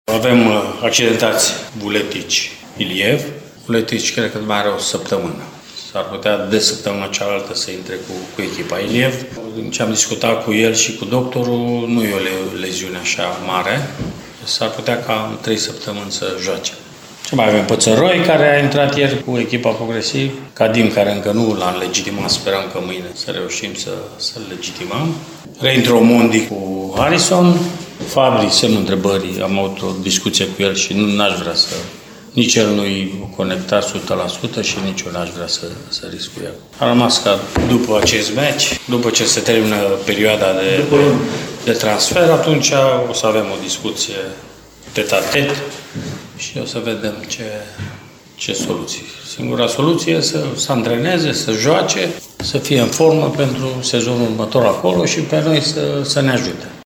Antrenorul Mircea Rednic despre situația lotului:
9-feb-21.03-Rednic-starea-lotului.mp3